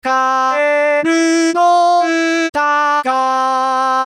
・歌声合成ツール「UTAU」
うはー！また更に自然になったよ！！